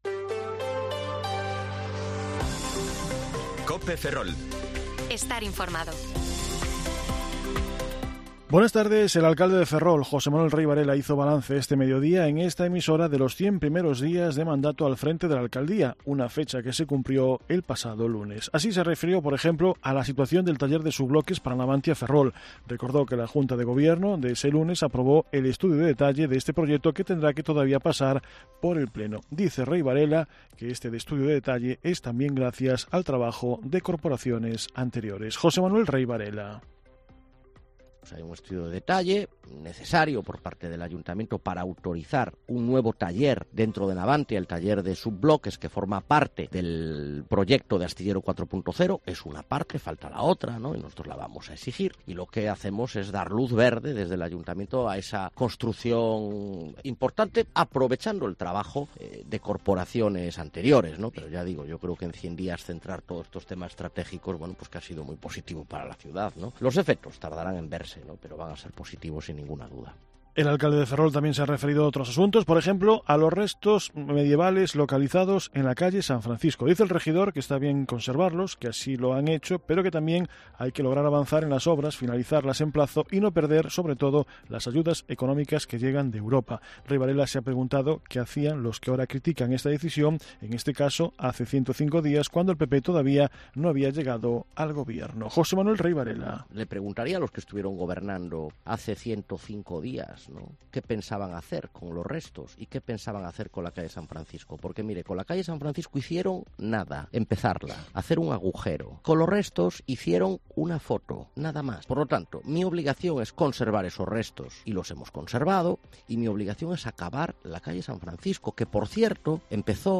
Informativo Mediodía COPE Ferrol 28/9/2023 (De 14,20 a 14,30 horas)